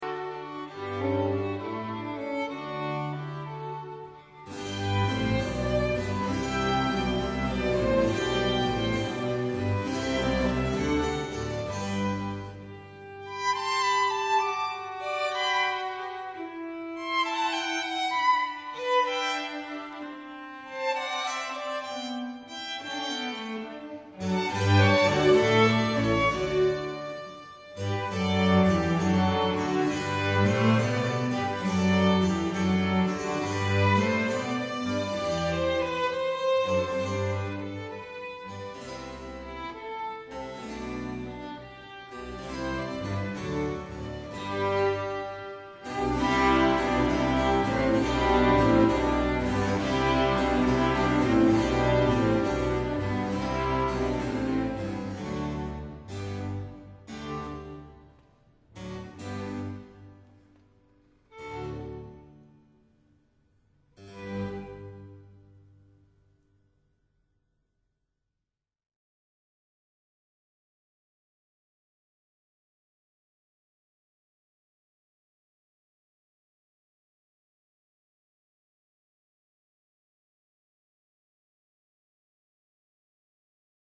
The Church of San Bartolomeo in Brugherio (MB)